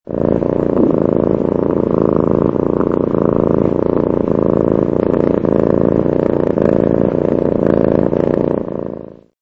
Cat Purr ringtone